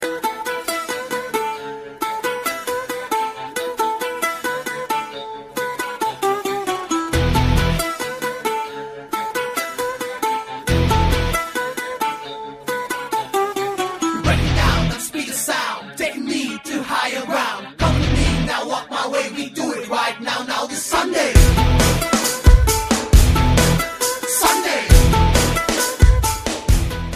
Sound Effects
notification blackberry messenger